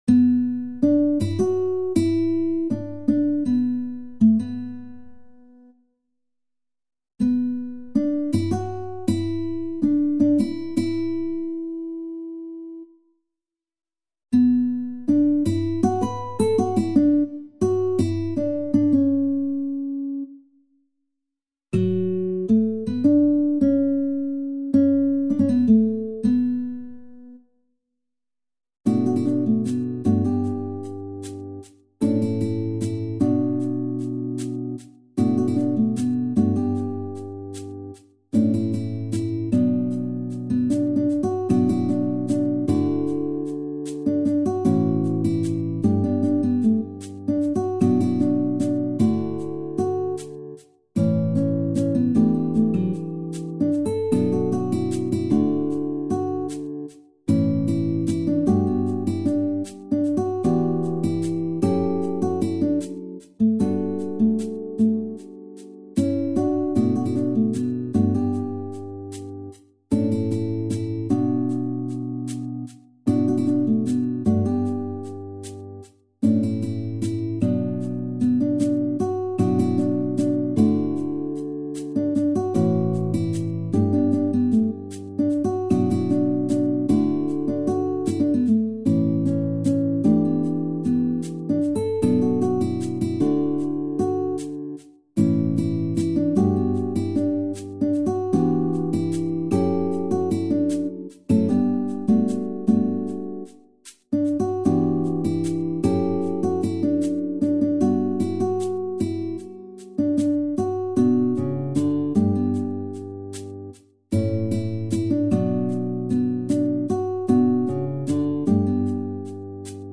SSAATTB | SSAA met solo's | SSSAA | SSAAB | SSAATB